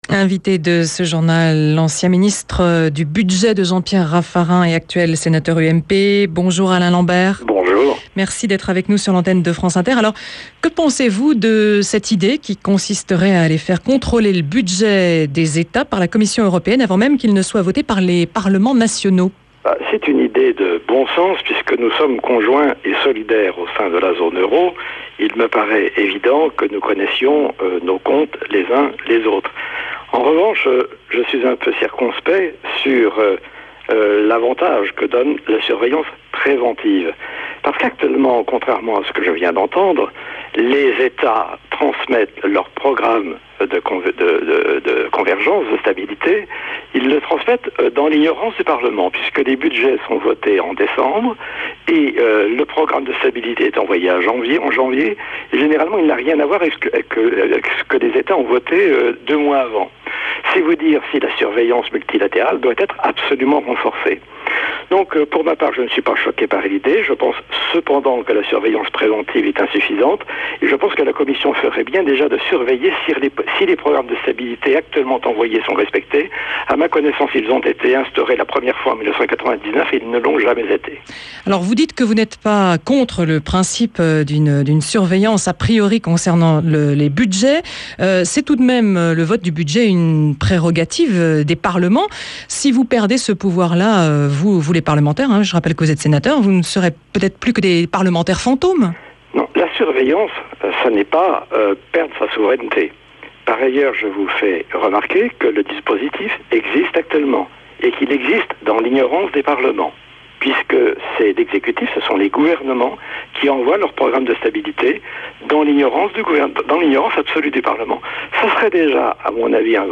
Interrogé tout à l’heure sur France Inter, j’ai constaté que l’amélioration de la « surveillance préventive » qui a été confiée à la Commission Européenne, lors du sommet du week-end dernier pour sortir de la crise financière, était perçue comme une atteinte à la souveraineté des Etats.